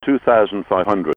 new 2500 and 100 above airbus callouts